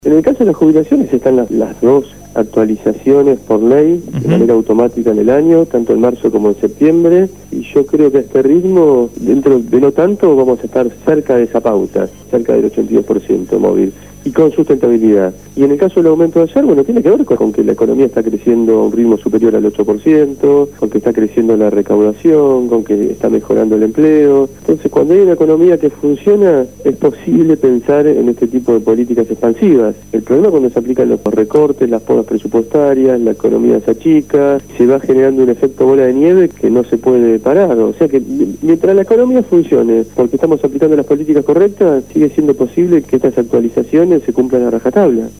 Ariel Pasini se refirió en esta entrevista a la Asignación Universal por Hijo, las jubilaciones, el Banco del Sur, la Ley de Tierras y la economía argentina.